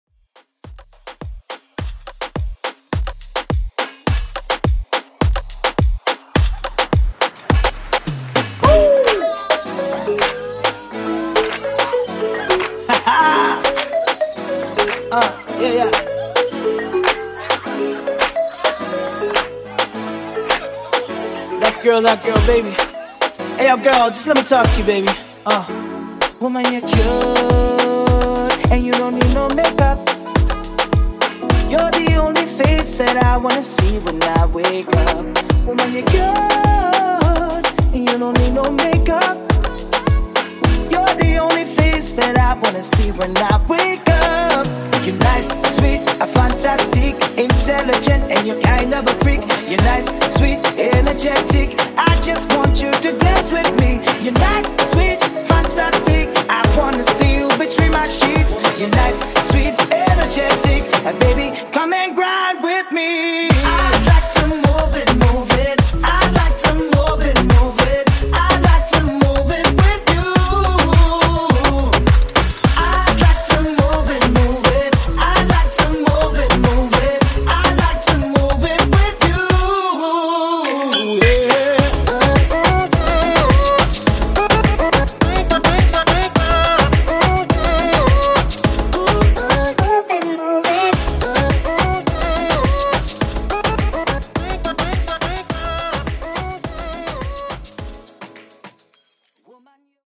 Personal Moombah)Date Added